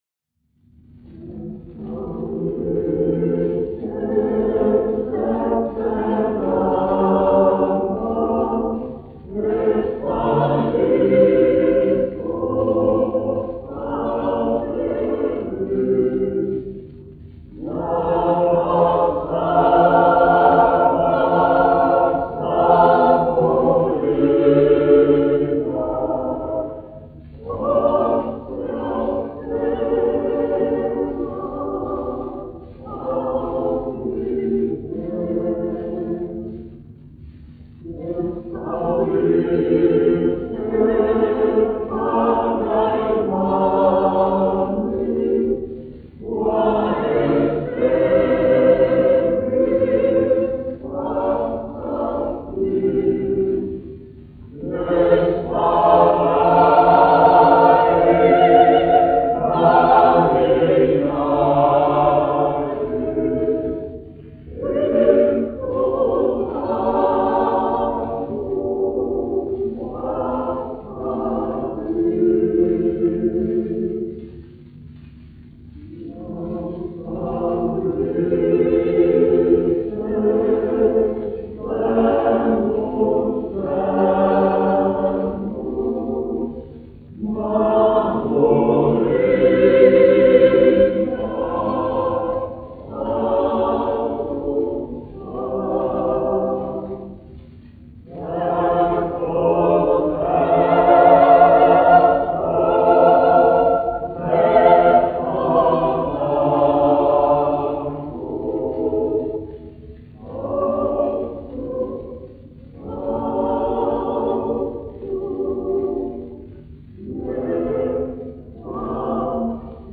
Daugava (koris), izpildītājs
1 skpl. : analogs, 78 apgr/min, mono ; 25 cm
Latviešu tautasdziesmas
Kori (jauktie)
Skaņuplate